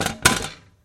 contact mic dumping rocks into toy truck
描述：contact mic on a metal toy truck while I drop pebbles into the bed of the truck
标签： pebble piezo hit clatter rock clack tapping rocks metal rattle tap pebbles
声道立体声